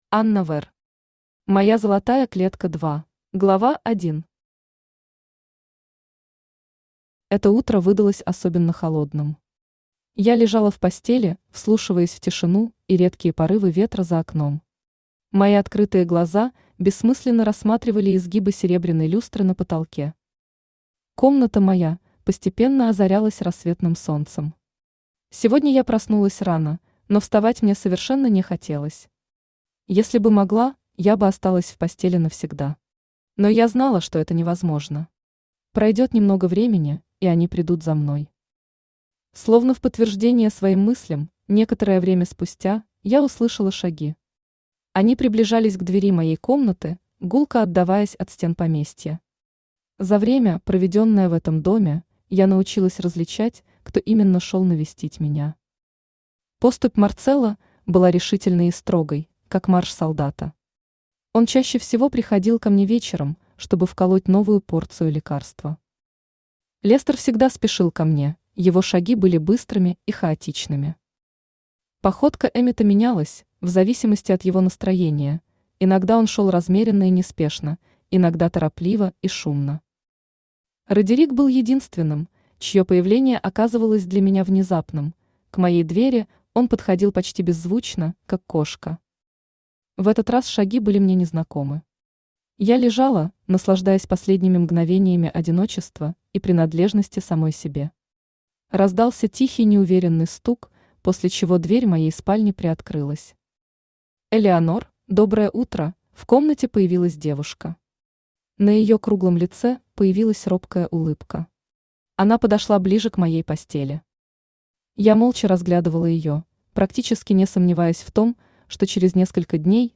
Aудиокнига Моя золотая клетка 2 Автор Анна Вэрр Читает аудиокнигу Авточтец ЛитРес.